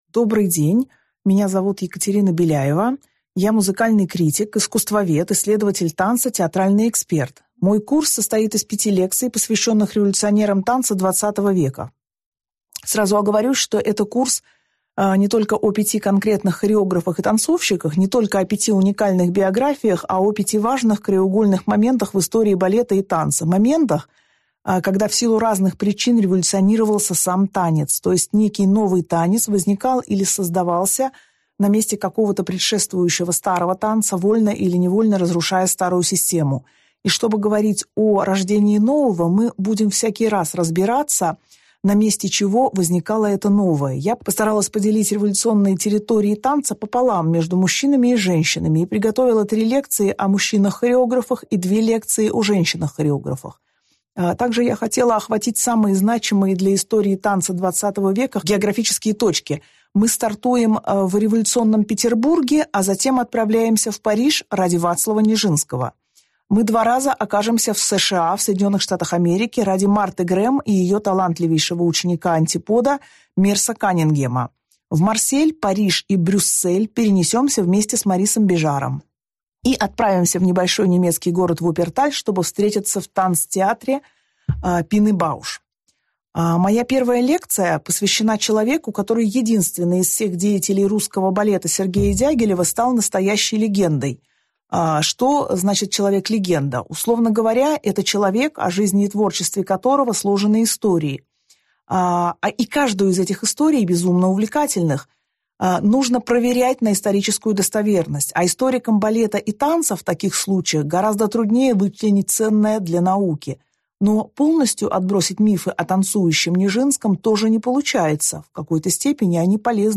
Аудиокнига Вацлав Нижинский: шаг в модернизм | Библиотека аудиокниг